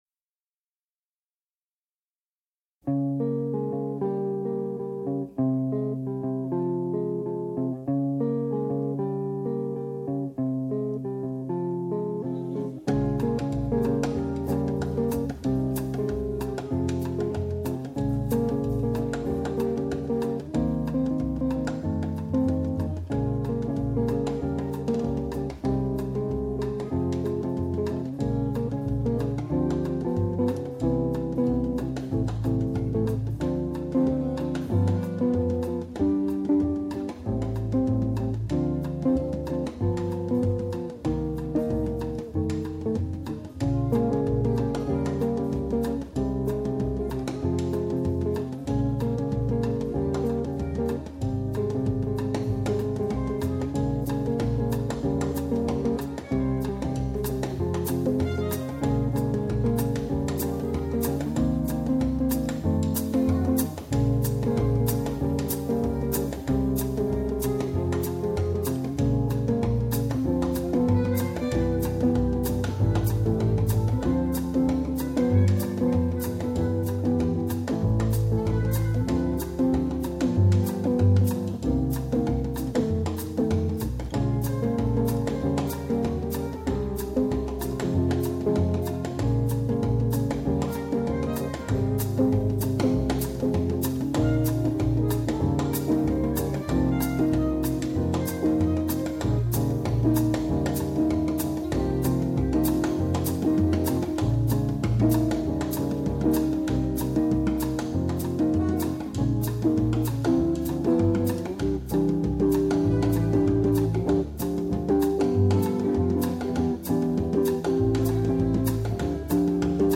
Bossa Antiqua Backing